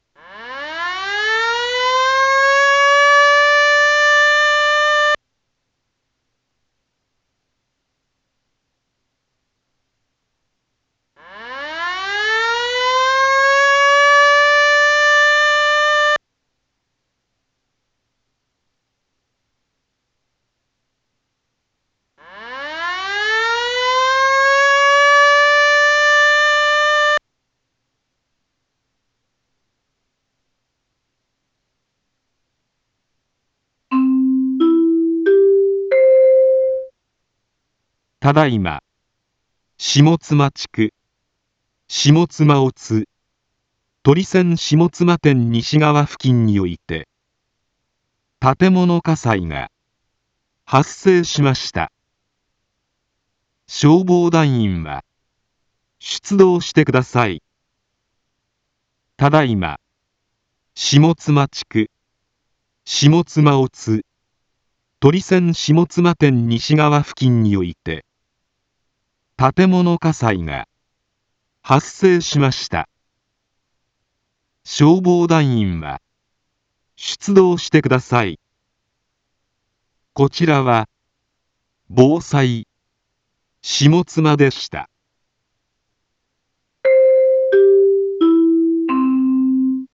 一般放送情報
Back Home 一般放送情報 音声放送 再生 一般放送情報 登録日時：2025-06-19 10:41:51 タイトル：火災報 インフォメーション：ただいま、下妻地区、下妻乙、とりせん下妻店西側付近において、 建物火災が、発生しました。